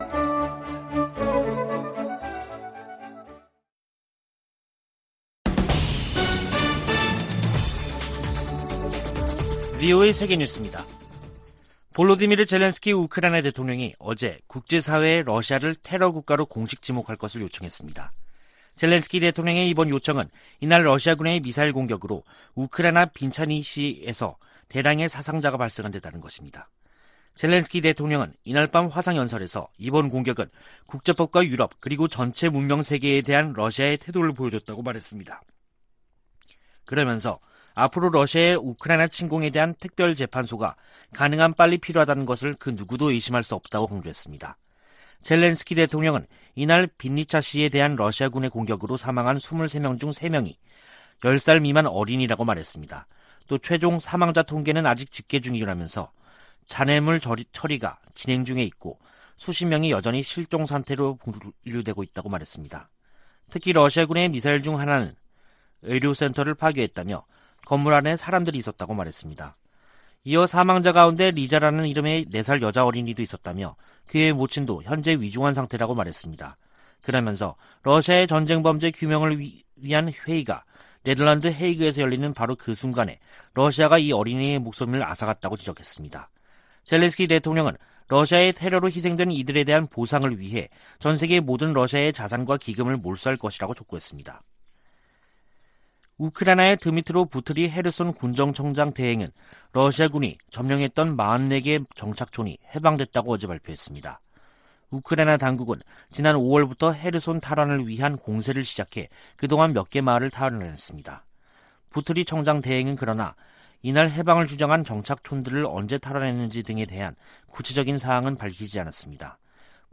VOA 한국어 간판 뉴스 프로그램 '뉴스 투데이', 2022년 7월 15일 3부 방송입니다. 재닛 옐런 미 재무장관은 오는 19일 방한에서 경제관계를 강화하고 대북 제재를 이행 문제 등을 논의할 것이라고 밝혔습니다. 미한 공군의 F-35A 스텔스 연합비행훈련은 북한의 도발에 전략자산으로 대응할 것이라는 경고를 보내는 것이라고 미국 군사전문가들은 평가했습니다. 마크 에스퍼 전 미 국방장관은 '쿼드' 확대의 필요성을 강조하며 적합한 후보국으로 한국을 꼽았습니다.